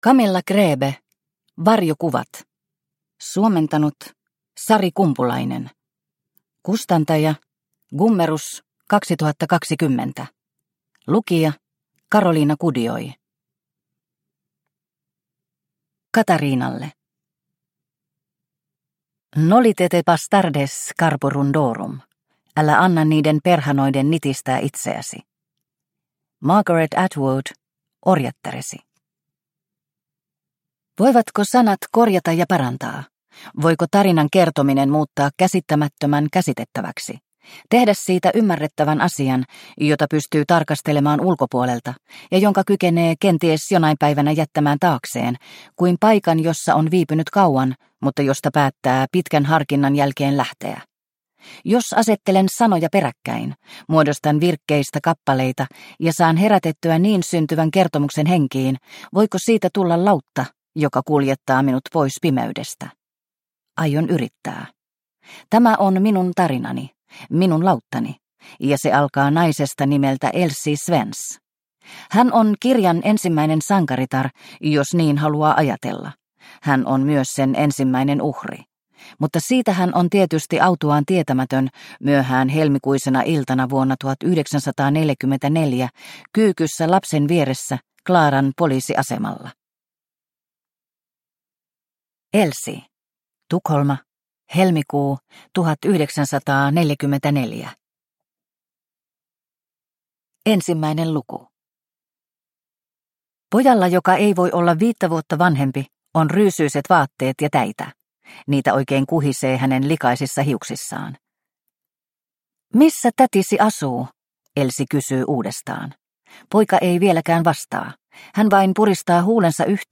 Varjokuvat – Ljudbok – Laddas ner